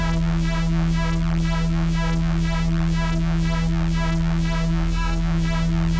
Index of /musicradar/dystopian-drone-samples/Tempo Loops/120bpm
DD_TempoDroneD_120-E.wav